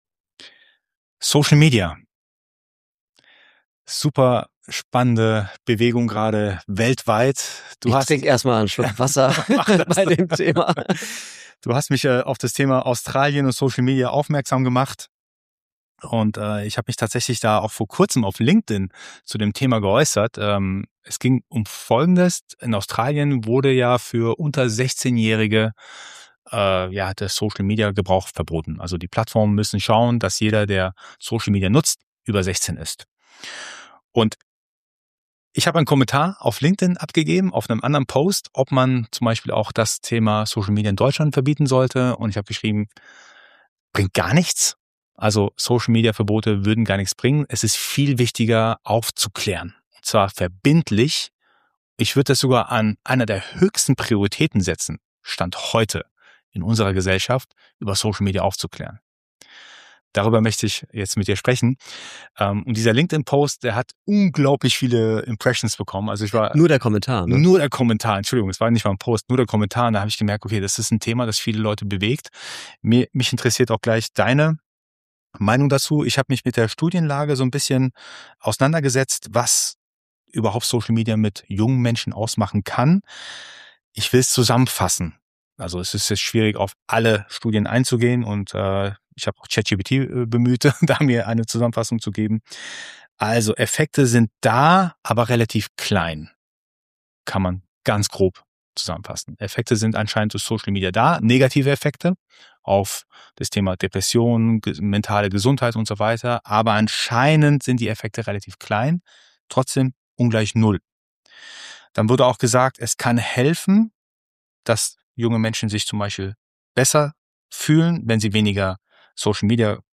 Wir reden über Medienkompetenz, Bildschirmzeit, brutale Inhalte, Cybermobbing, Verantwortung von Politik, Schulen und Eltern und darüber, warum Aufklärung wichtiger sein könnte als jedes Verbot. Wir diskutieren ehrlich, auch persönlich.